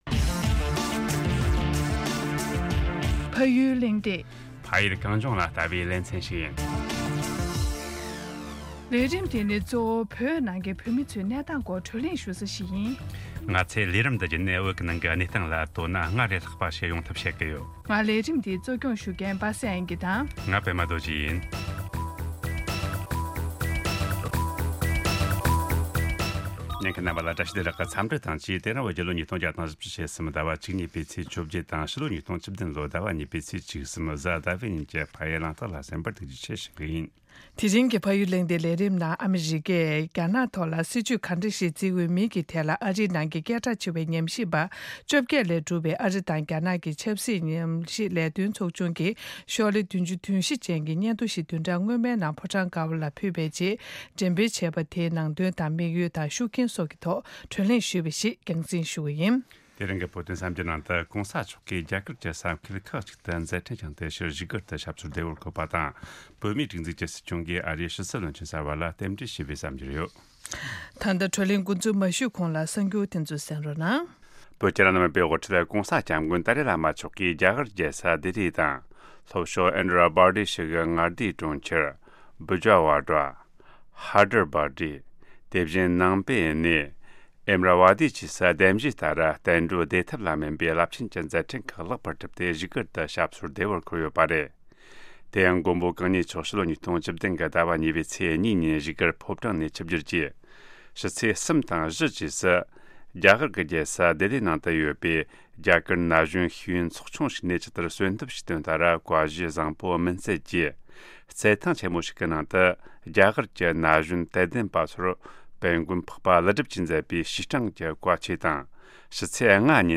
དེ་རིང་གི་ཕ་ཡུལ་གླེང་སྟེགས་ནང་ཨ་རིས་རྒྱ་ནག་གི་སྟེང་དུ་སྲིད་ཇུས་ག་འདྲ་ཞིག་འཛིན་འོས་པའི་ཐད་ཨ་རིའི་ནང་གི་སྐད་གྲགས་ཆེ་བའི་ཉམས་ཞིབ་པ་༡༨་ཀྱིས་གྲུབ་པའི་ཨ་རི་དང་རྒྱ་ནག་ཆབ་སྲིད་སྲིད་ཇུས་ལས་དོན་ཚོགས་ཆུང་གིས་ཤོག་ལྷེ་༧༤་ཅན་གྱི་སྙན་ཐོ་ཞིག་བདུན་ཕྲག་སྔོན་མའི་ནང་ཕོ་བྲང་དཀར་པོར་ཕུལ་རྗེས་འགྲེམ་སྤེལ་བྱས་པ་དེའི་ནང་དོན་དང་དམིགས་ཡུལ། ཤུགས་རྐྱེན་བཅས་ཀྱི་ཐད་བགྲོ་གླེང་ཞུས་པ་རྒྱང་སྲིང་ཞུ་རྒྱུ་ཡིན།